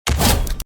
Fortnite Elimination Sound Button Soundboard: Play Instant Sound Effect Button
This high-quality sound effect is part of our extensive collection of free, unblocked sound buttons that work on all devices - from smartphones to desktop computers.